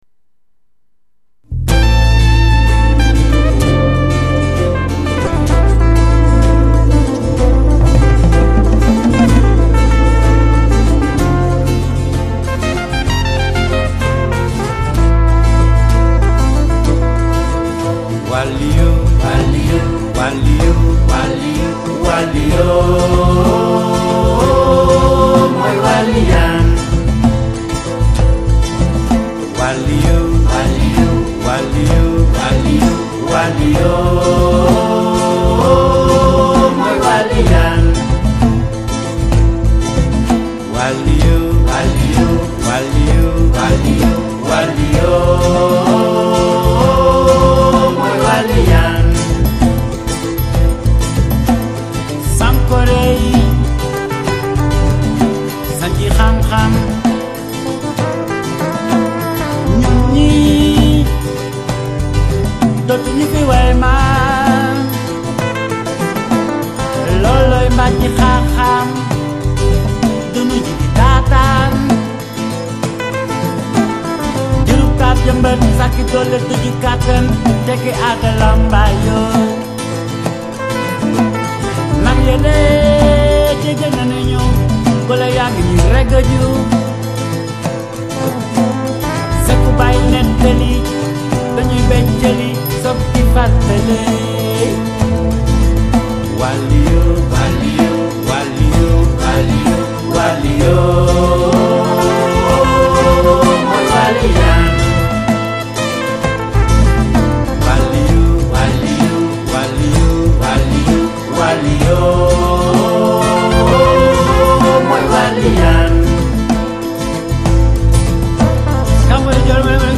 古典与现代的美妙结合，将古筝、吉他、Mandolin相完美的融合在一起。再用英文主唱，国语和声，很有创意！